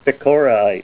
Help on Name Pronunciation: Name Pronunciation: Pecoraite + Pronunciation